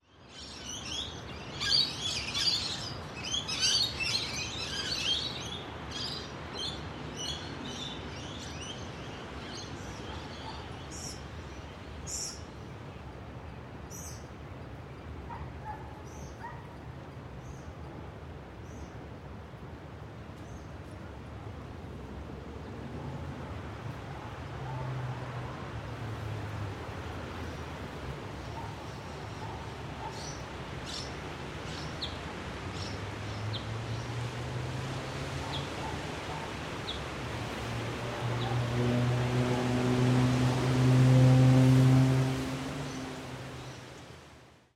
Загородные звуки Австралии